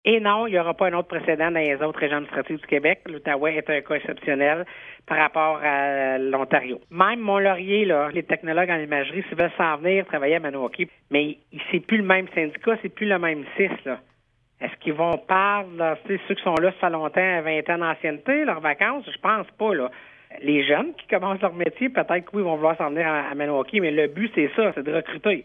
De plus, les technologues de Shawville, de Wakefield et du CLSC de St-André-Avelin, n’y avaient pas droit, une situation qu’ont dénoncée les élus de l’Outaouais, comme le fait valoir la préfète de la Vallée-de-la-Gatineau, Chantal Lamarche :